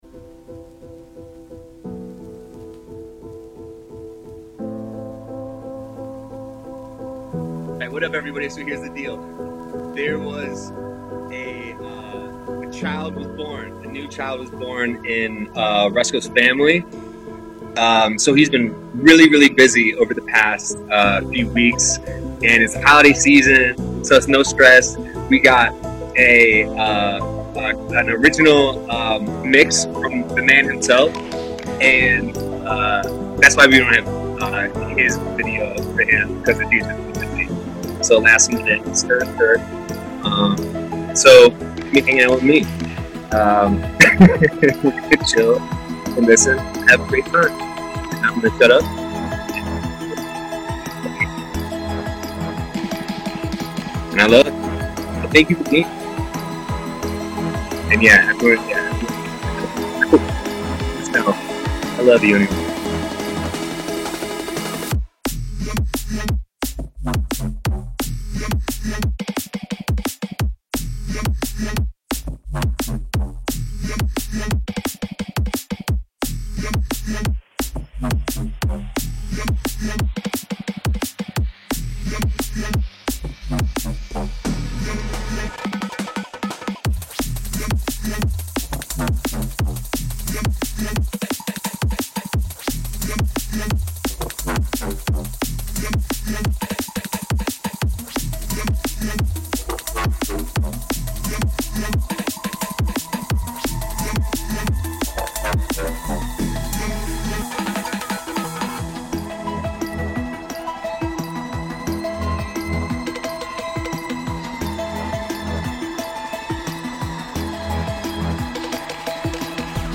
Genre: Dubstep